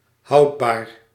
Ääntäminen
France (Paris): IPA: [dø]